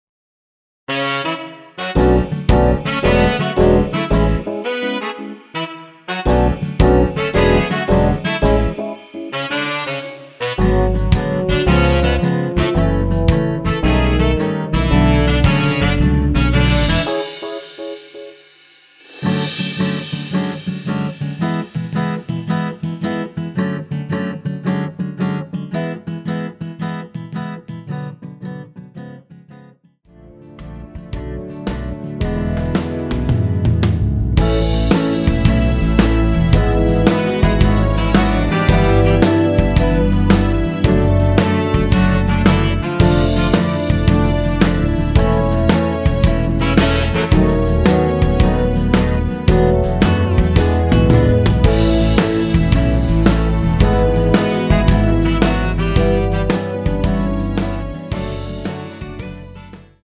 원키에서(-5)내린 남성분이 부르실수 있는 키의 MR입니다.(미리듣기 확인)
앞부분30초, 뒷부분30초씩 편집해서 올려 드리고 있습니다.
중간에 음이 끈어지고 다시 나오는 이유는